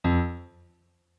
MIDI-Synthesizer/Project/Piano/21.ogg at 51c16a17ac42a0203ee77c8c68e83996ce3f6132